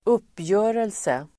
Uttal: [²'up:jö:relse]